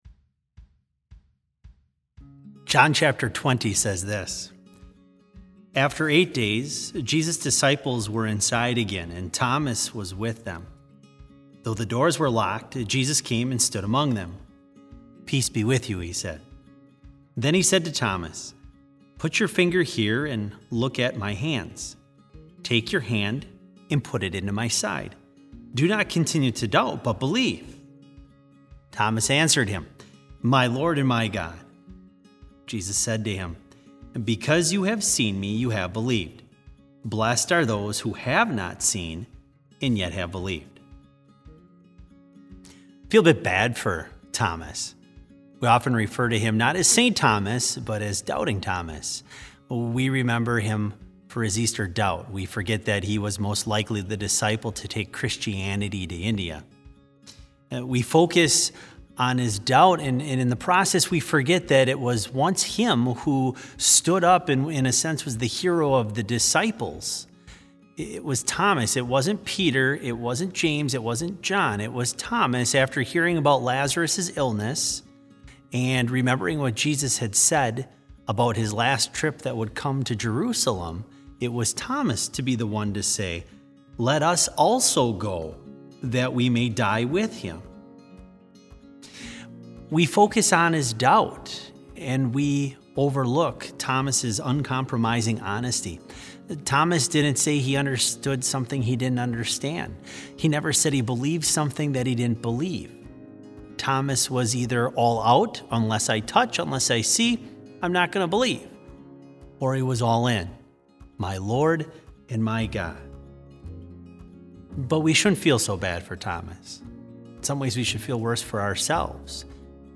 Complete service audio for BLC Devotion - April 17, 2020